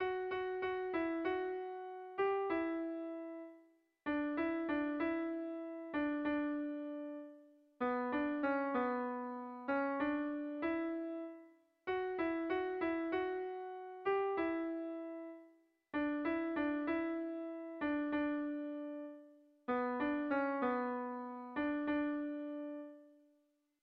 Lauko txikia (hg) / Bi puntuko txikia (ip)
A1A2